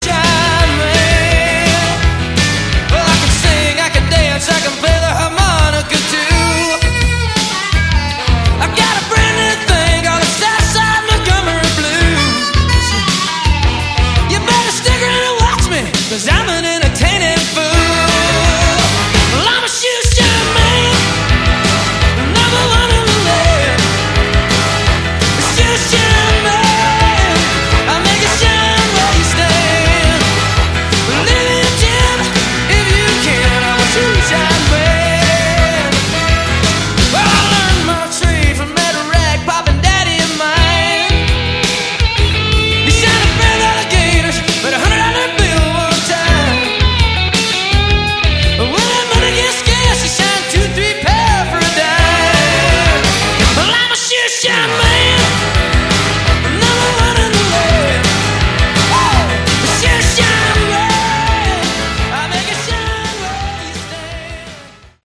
Category: AOR
piano, keyboards
electric guitar, mandolin